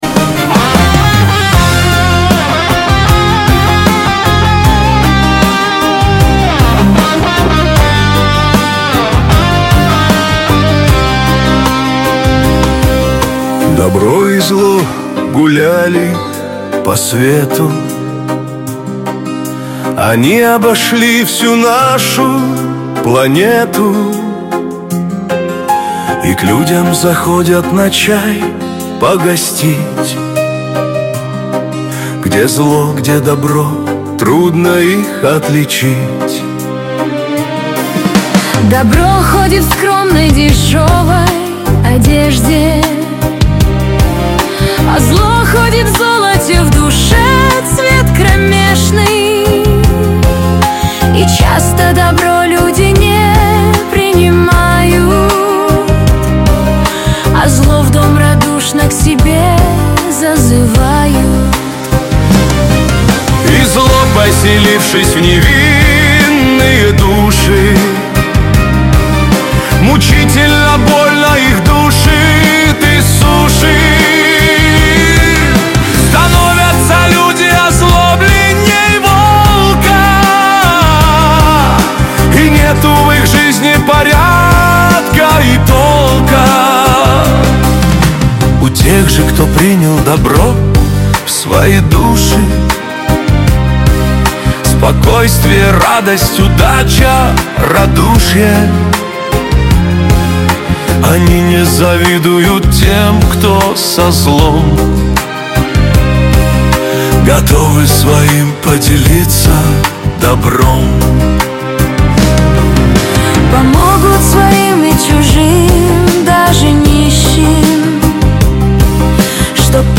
Качество: 128 kbps, stereo
Стихи, Нейросеть Песни 2025